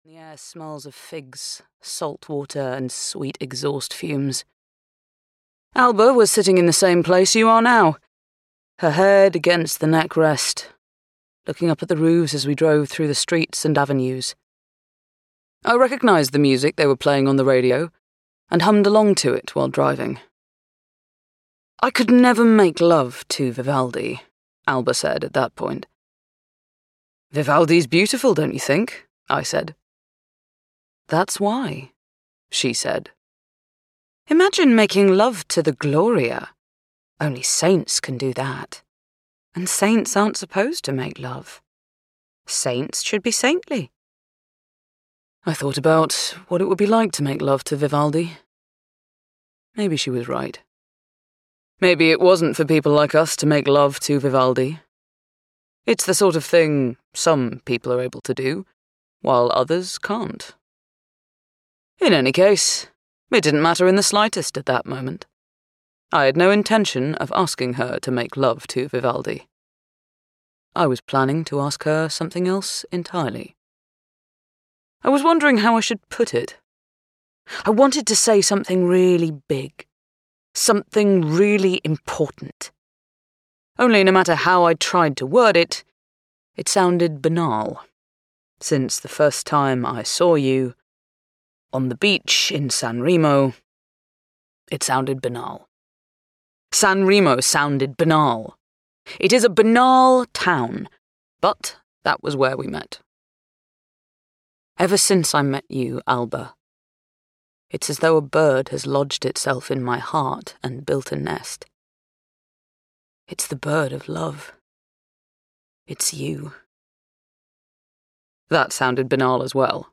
Audio knihaBret Easton Ellis and the Other Dogs (EN)
Ukázka z knihy